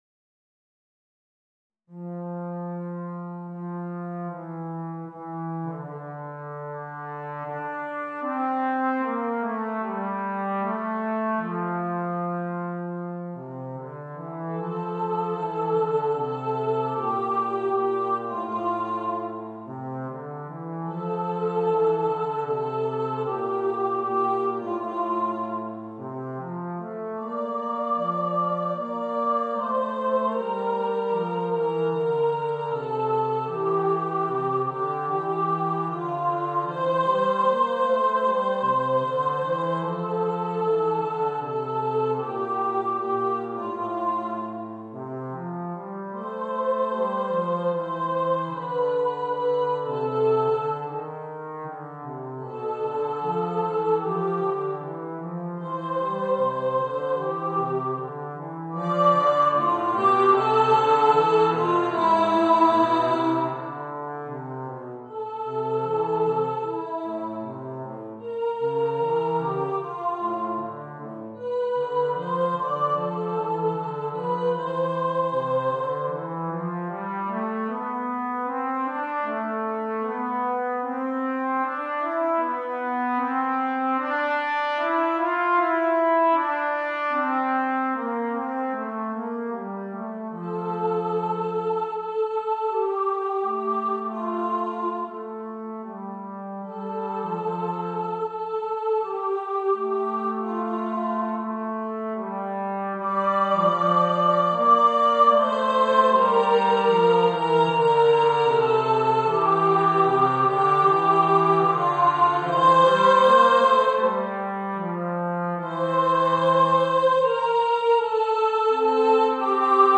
Voicing: Voice and Trombone